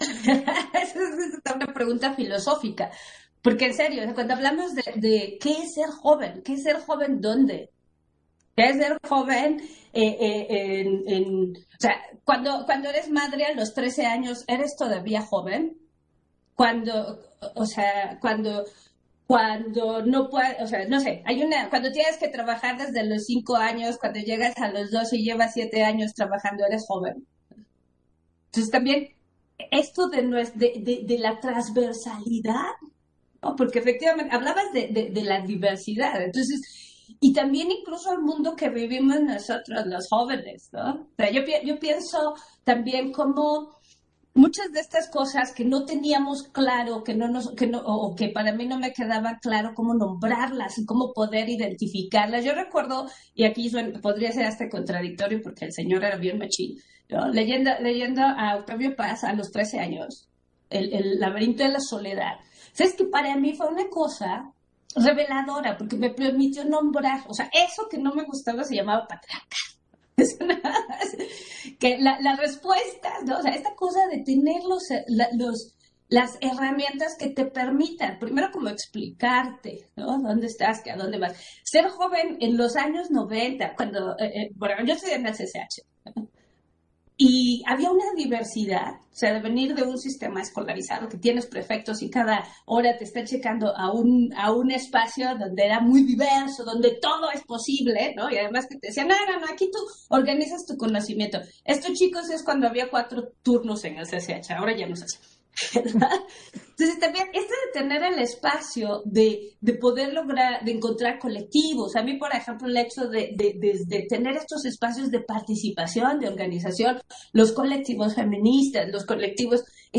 Intervención de Norma De La Cruz, en la segunda mesa de diálogo, Derechos políticos y electorales de las mujeres jóvenes